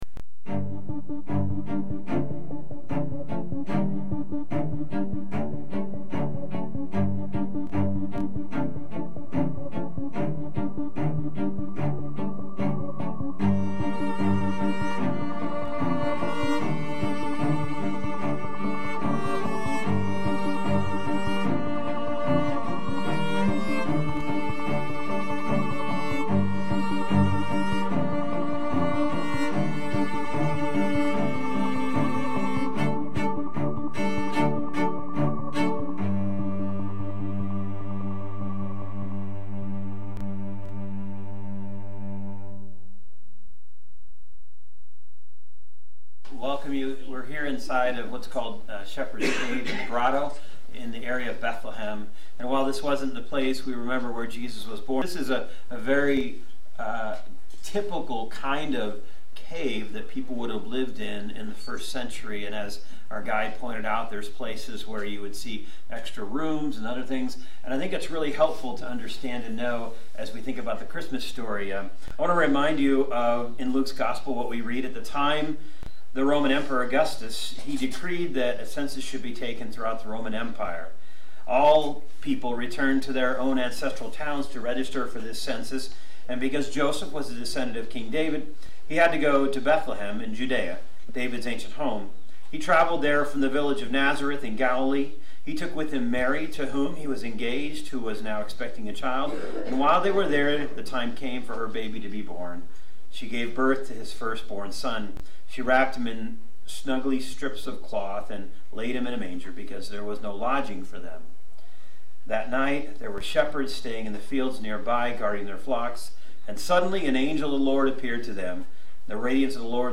Service Type: Summit Weekend Service